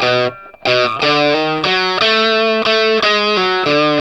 WALK1 60 B.A.wav